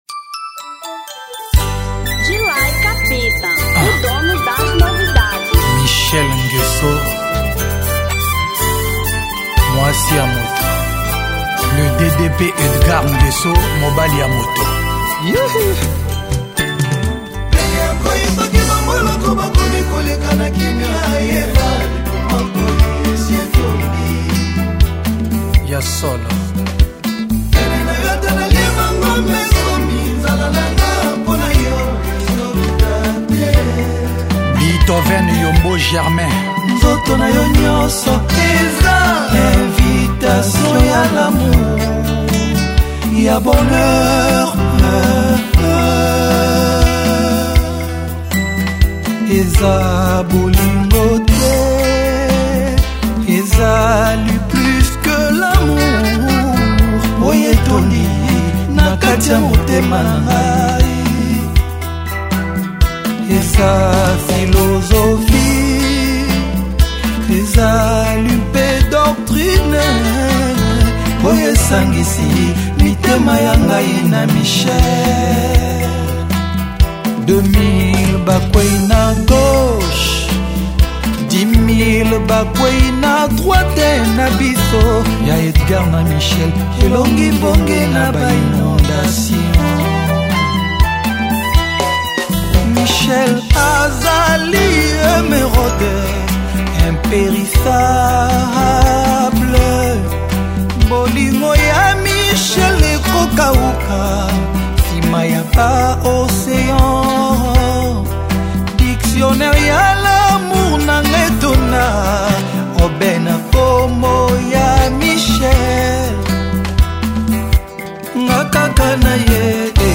Rumba 2013